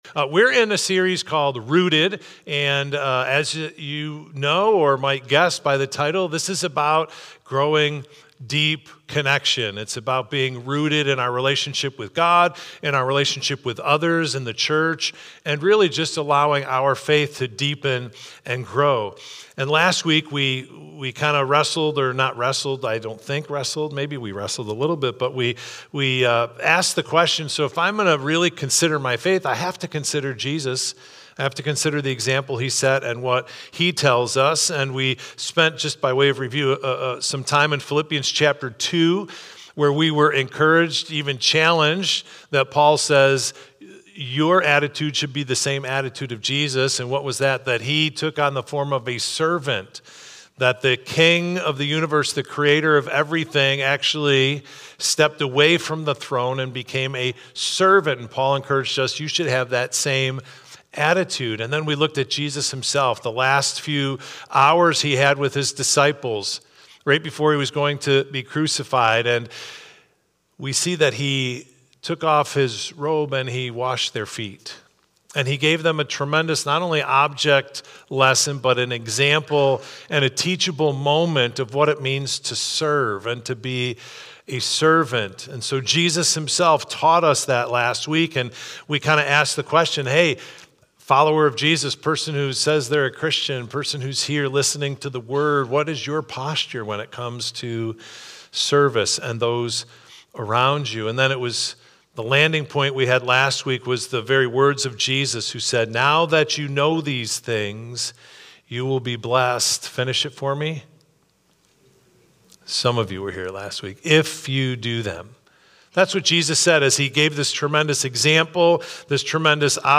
Victor Community Church Sunday Messages / Rooted: How Do I Use My Gifts to Serve Others?